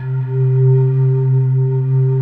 Index of /90_sSampleCDs/USB Soundscan vol.28 - Choir Acoustic & Synth [AKAI] 1CD/Partition D/19-IDVOX FLT